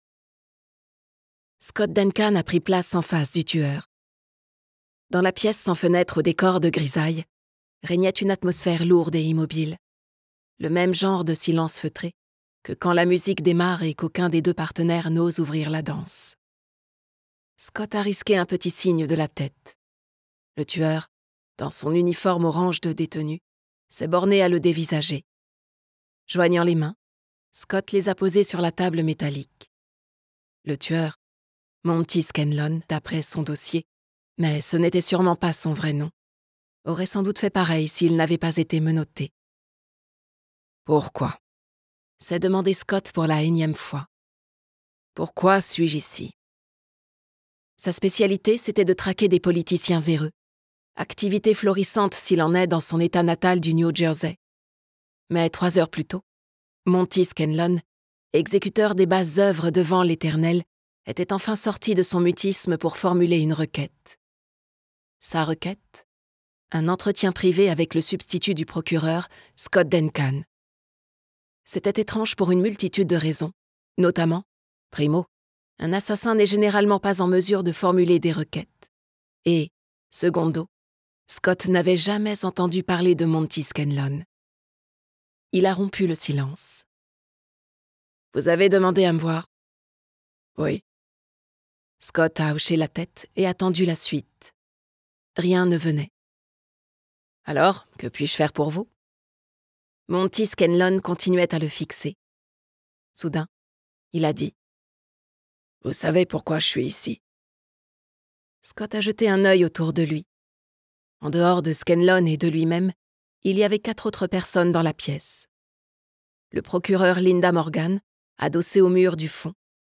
Télécharger le fichier Extrait MP3